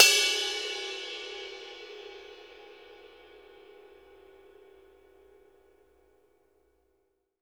BELL      -R.wav